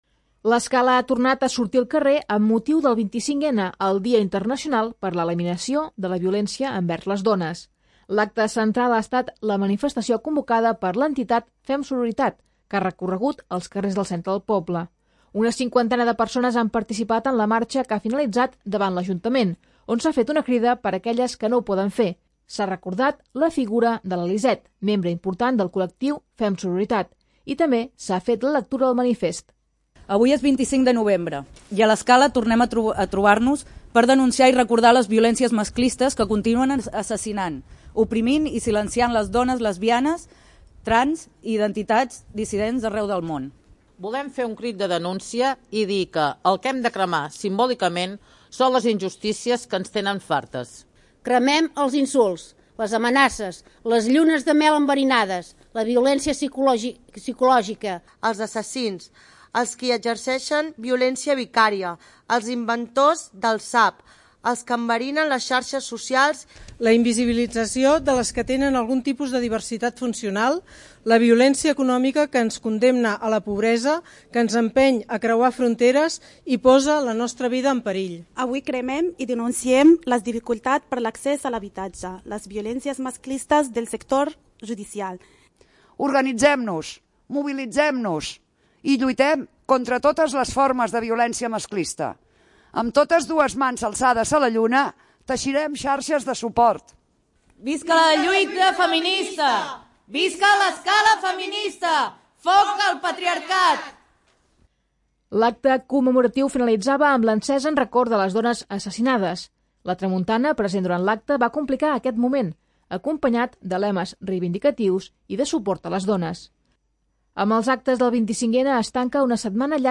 La Tramuntana present durant l'acte, va complicar aquest moment acompanyat de lemes reivindicatius i de suport a les dones.